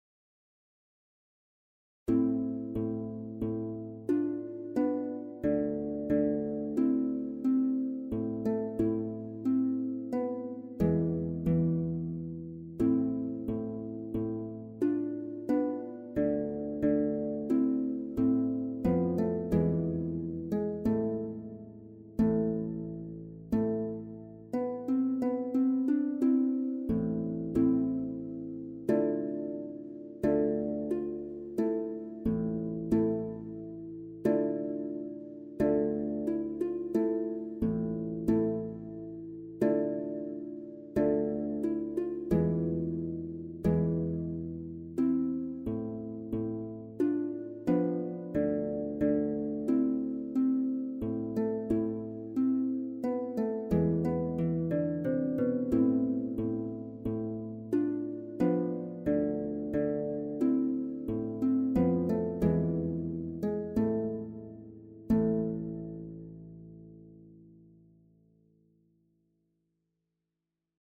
TORBAN/BAROQUE LUTE SOLOS